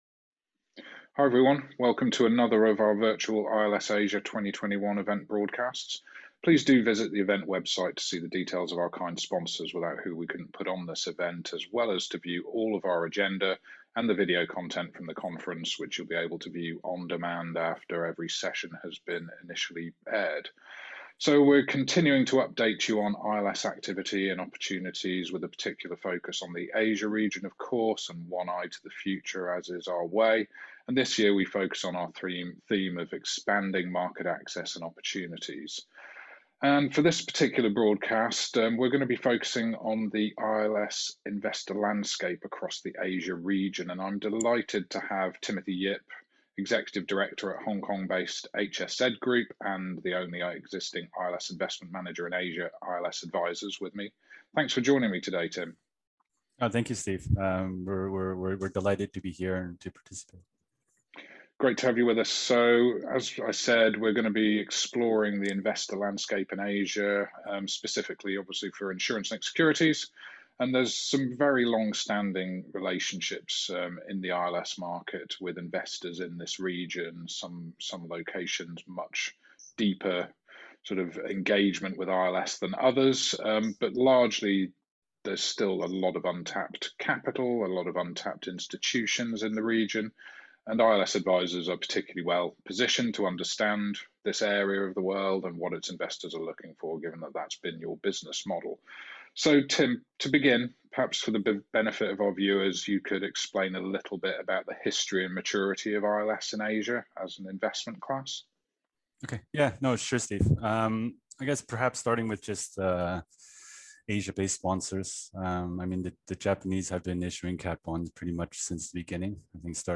The Asian insurance-linked securities investor landscape - ILS Asia 2021 interview